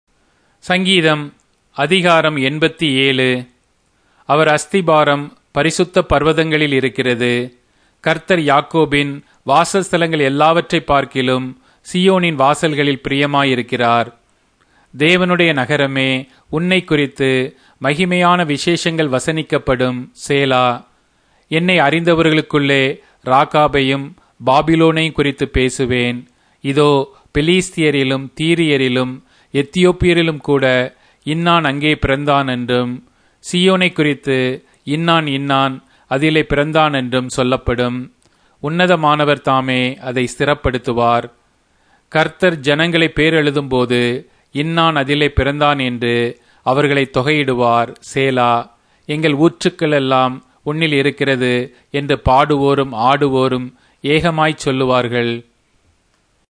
Tamil Audio Bible - Psalms 20 in Mhb bible version